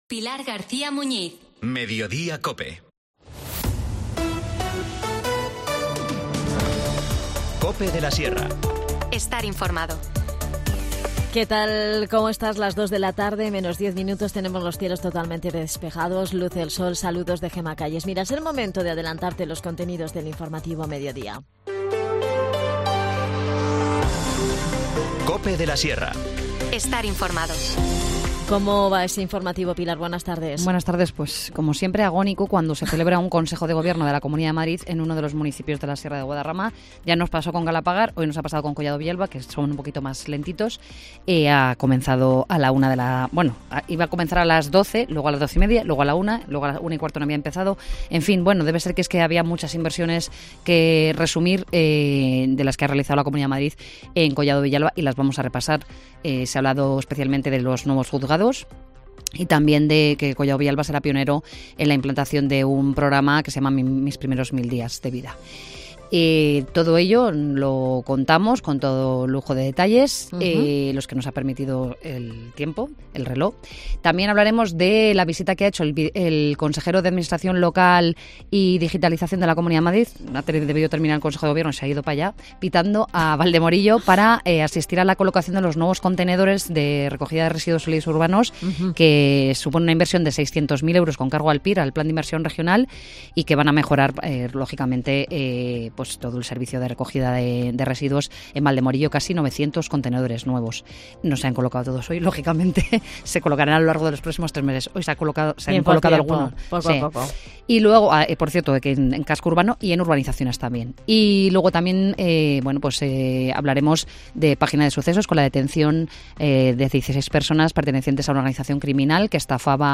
Te contamos las últimas noticias de la Sierra de Guadarrama con los mejores reportajes y los que más te interesan y las mejores entrevistas, siempre pensando en el oyente.